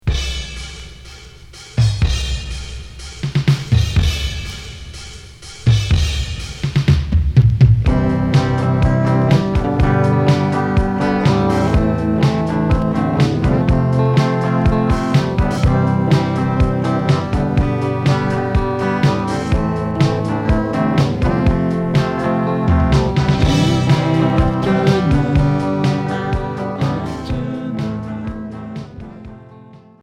Pop indé